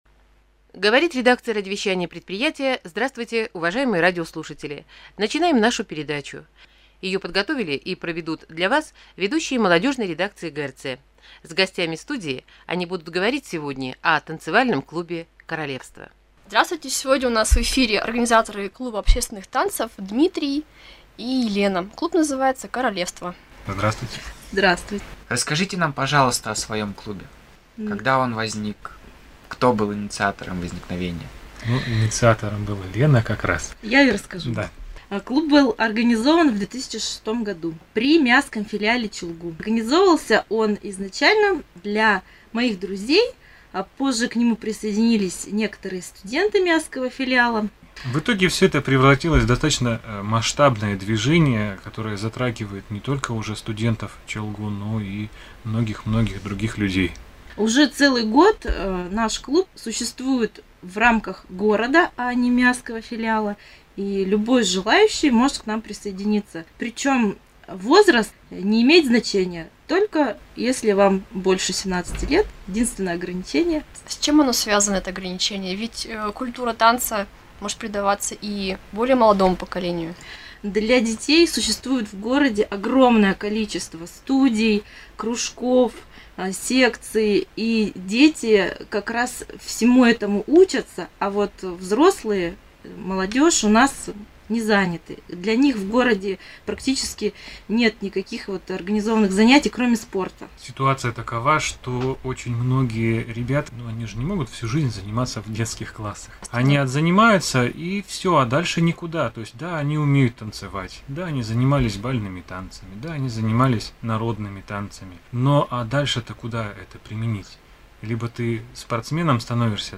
В конце лета прошлого года (29 августа 2012 г.) нас пригласили рассказать в передаче на радио ГРЦ о нашем клубе.